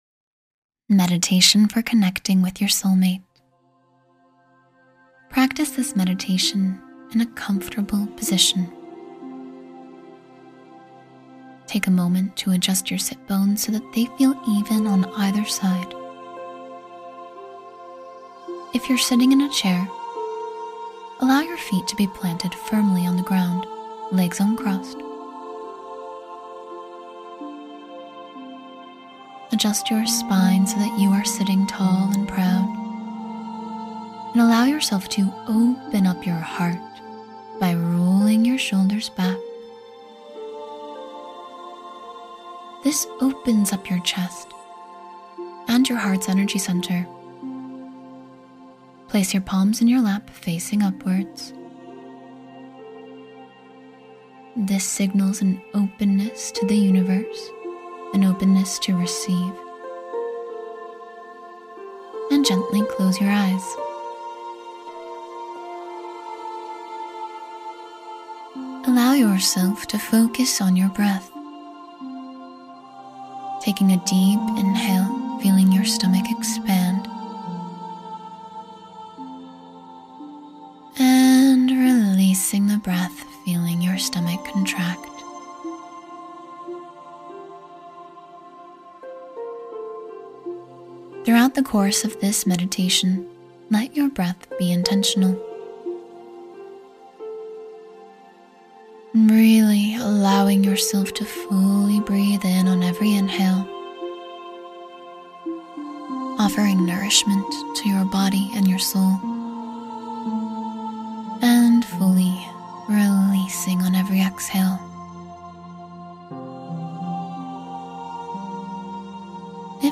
Open Your Heart — A Guided Meditation for Soul Connection and Love